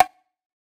Perc [ Dubai Shit ].wav